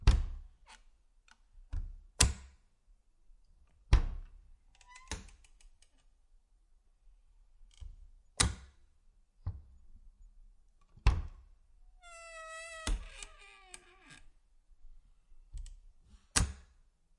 随机的 "门木柜开合1
描述：门木柜打开close1.flac